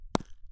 sound / sfx / ball / grass4.wav
grass4.wav